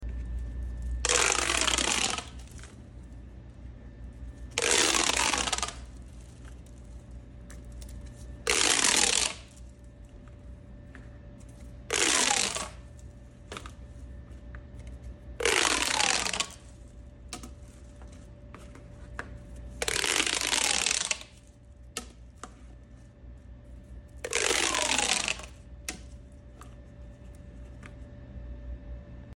popo sound Pipe tube sound effects free download